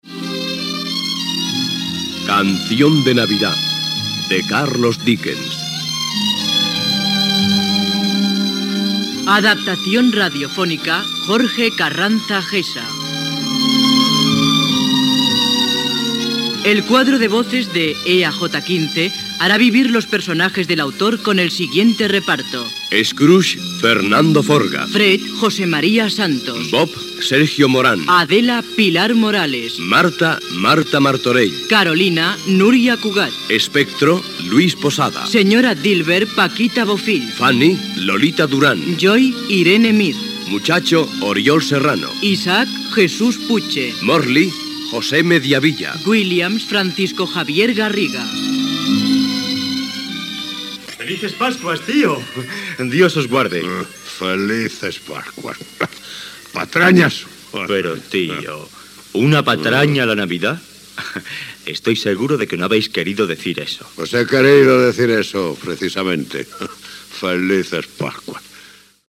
Teatro Radiofónico: careta de presentació - Radio España, 1963